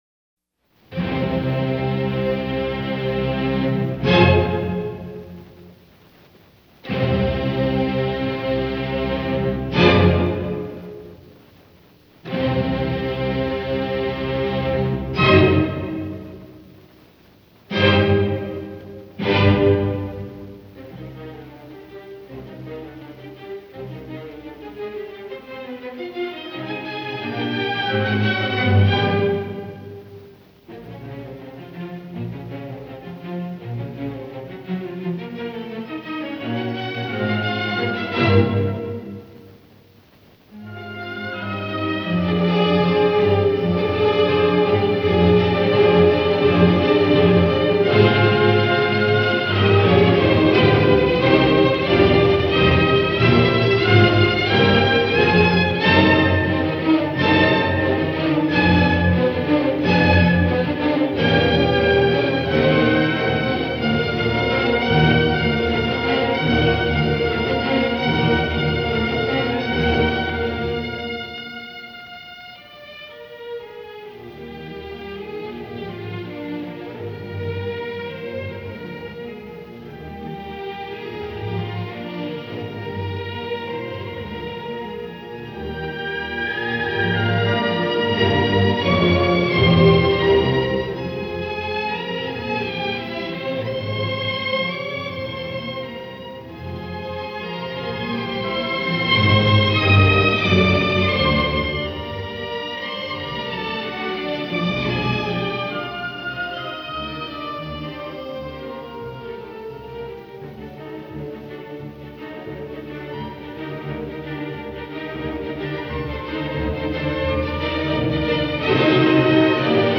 Gramophone recording